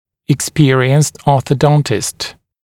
[ɪk’spɪərɪənst ˌɔːθə’dɔntɪst] [ek-][ик’спиэриэнст ˌо:сэ’донтист] [эк-]опытный (врач-) ортодонт